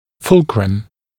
[‘fulkrəmˌ ‘fʌlkrəm] [‘фулкрэмˌ ‘фалкрэм]